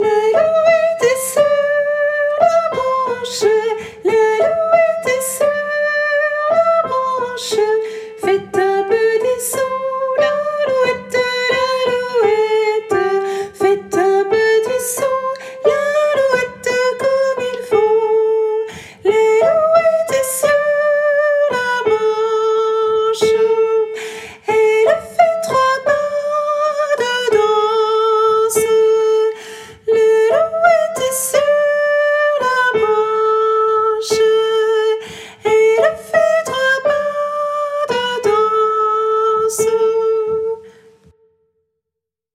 - Œuvre pour choeur à 4 voix mixtes (SATB)
- chanson populaire de Lorraine
MP3 versions chantées
Soprano